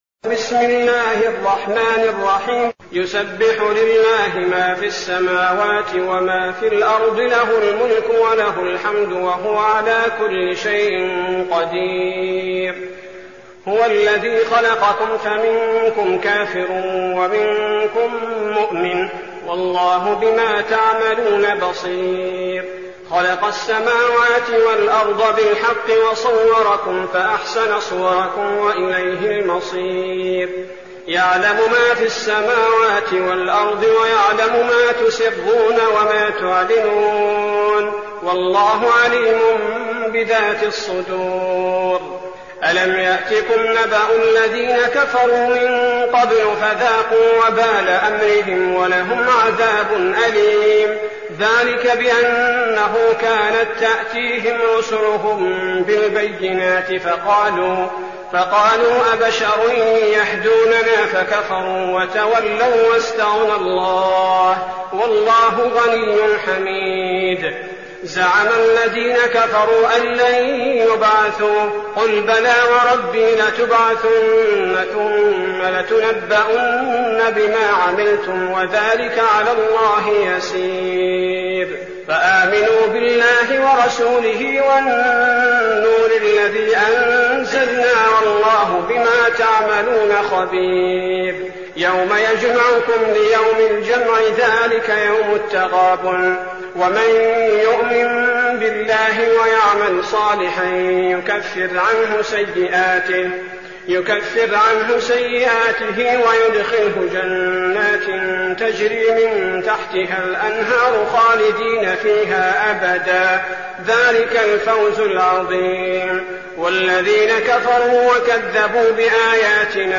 المكان: المسجد النبوي الشيخ: فضيلة الشيخ عبدالباري الثبيتي فضيلة الشيخ عبدالباري الثبيتي التغابن